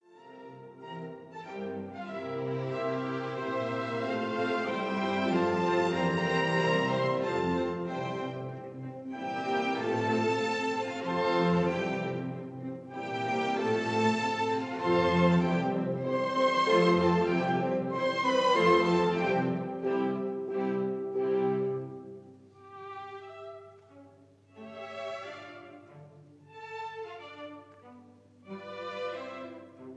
andante cantabile con moto